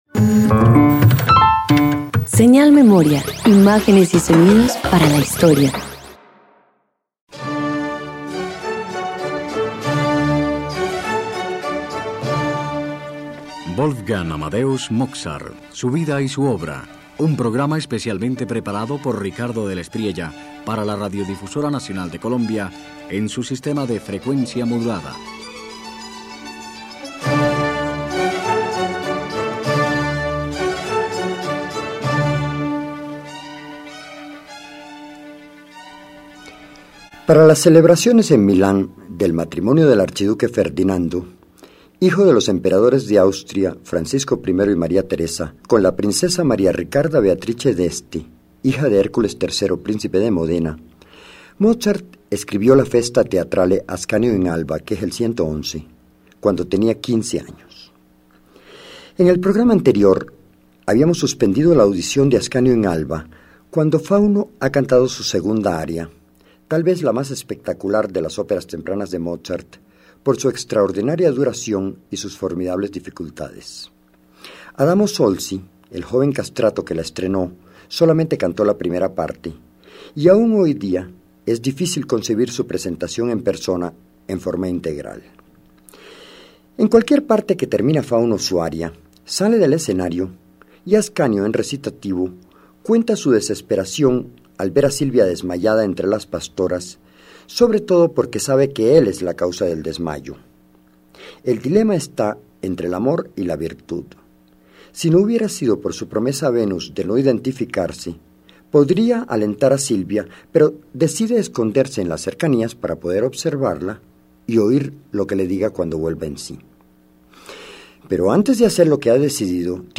El desenlace llega con dulzura: Silvia despierta, Ascanio se revela, Venus bendice la unión. Los coros celebran el amor y la virtud, mientras la música asciende como luz de ceremonia.